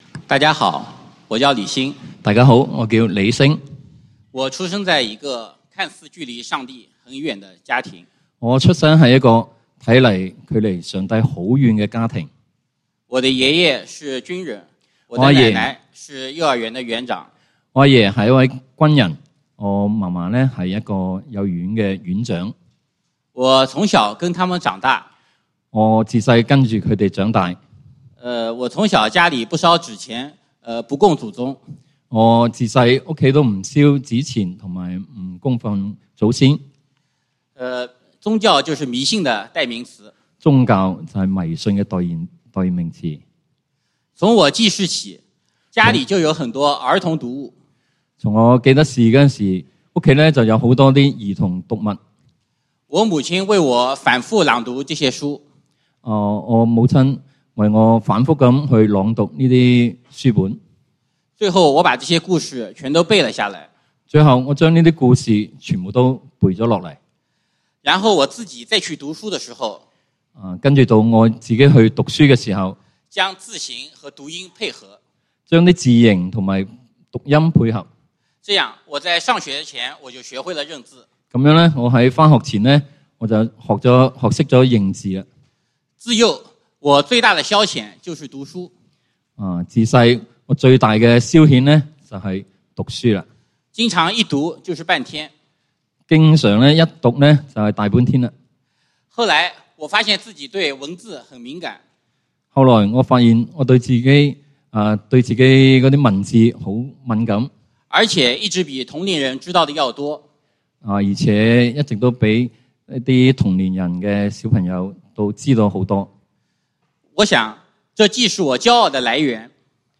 復活節崇拜：出路 (經文：哥林多前書15:1-11) | External Website | External Website